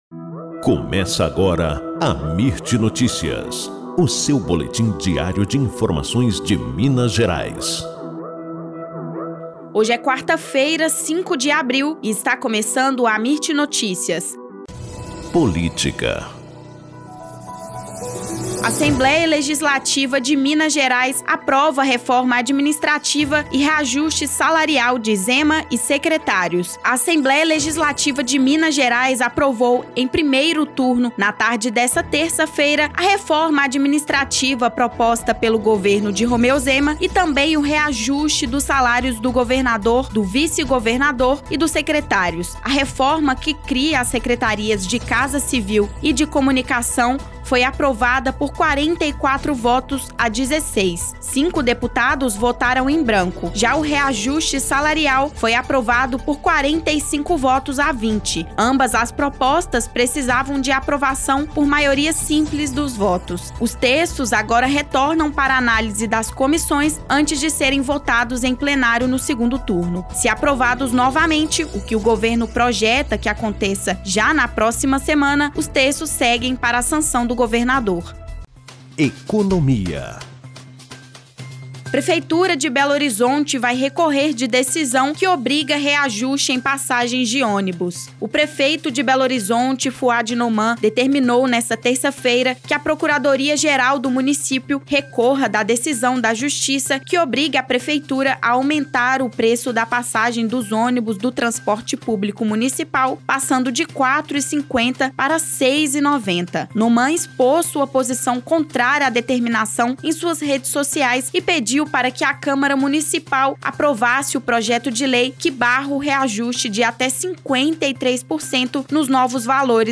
Boletim Amirt Notícias – 5 de abril